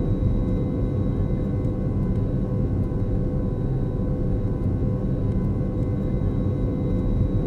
combat / aircraft / eng.wav